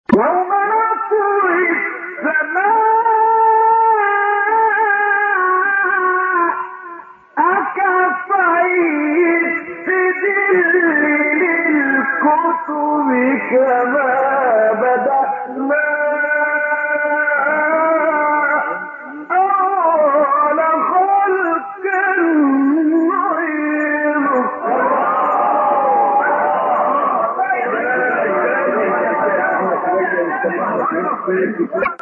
سوره : انبیاء آیه : 104 استاد : محمد عبدالعزیز حصان مقام : مرکب خوانی( رست * حجاز) قبلی بعدی